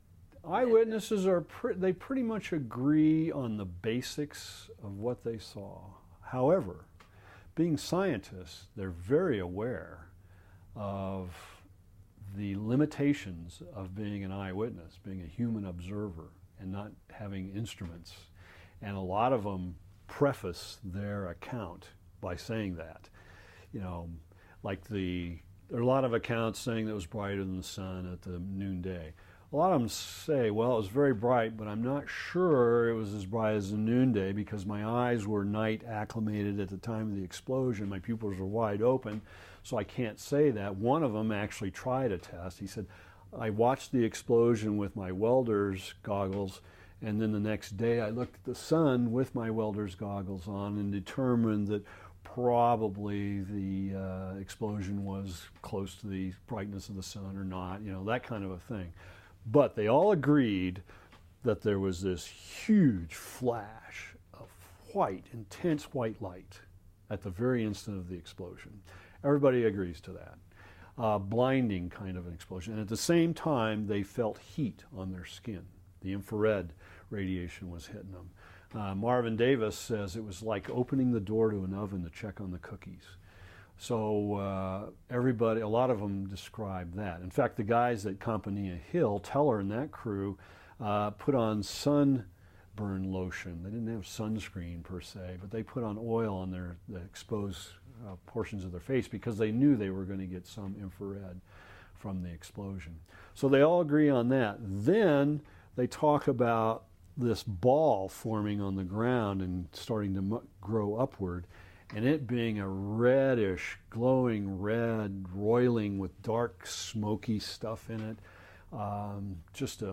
Largely from men, and often scientists, these add much to our understanding of sound at the Trinity test. There are so many of these stories, and so often told, that a younger figure who had not witnessed the tests but spent years at other military sites around those who had could recount much the same story: